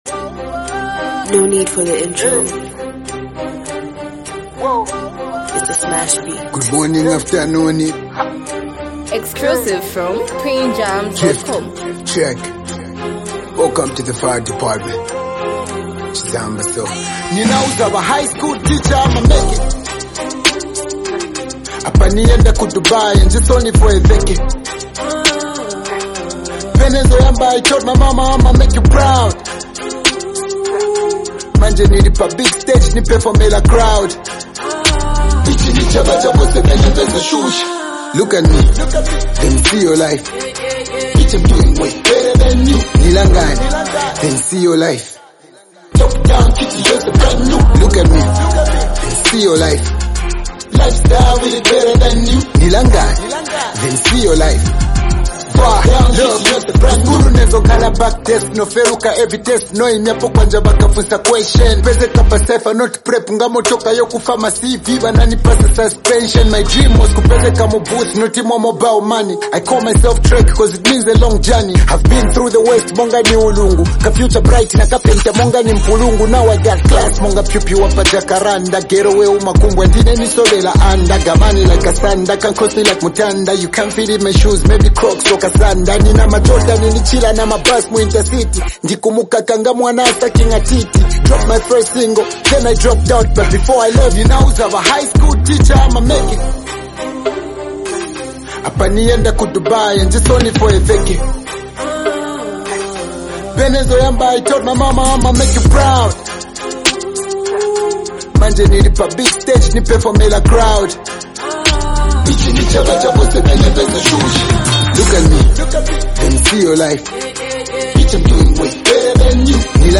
hip-hop record